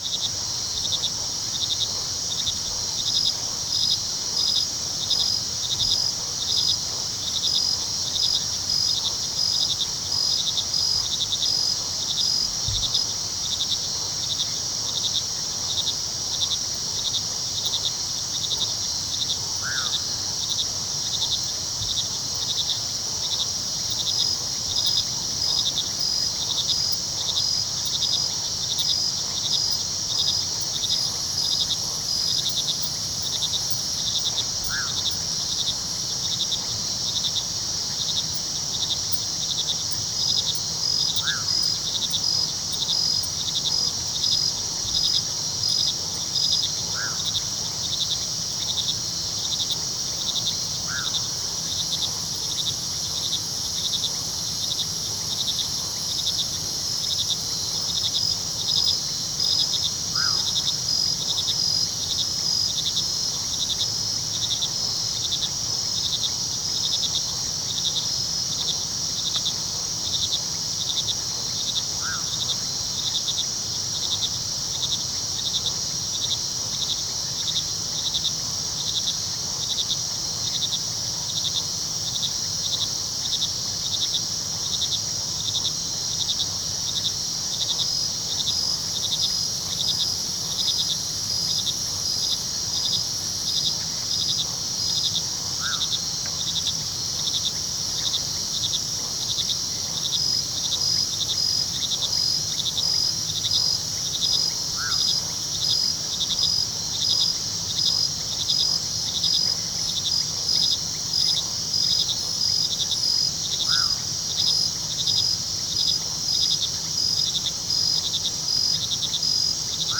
Forest Night
forest-night-2.ogg